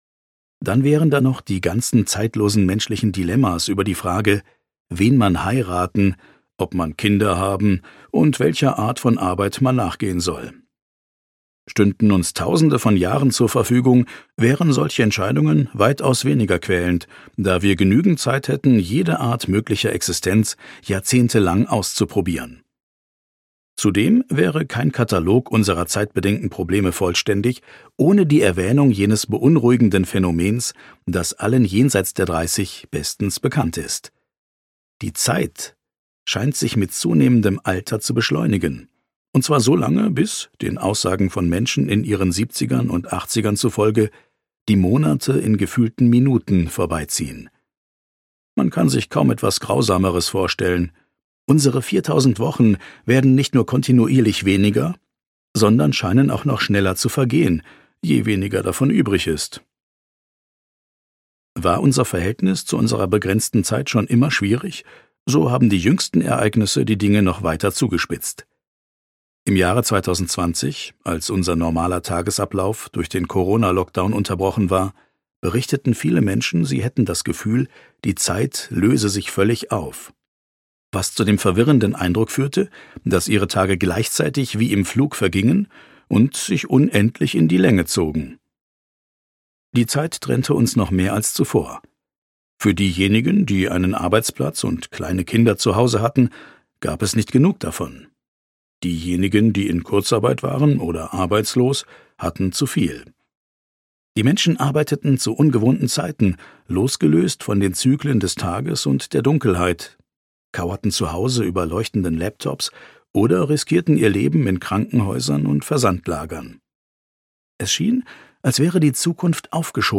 4000 Wochen (DE) audiokniha
Ukázka z knihy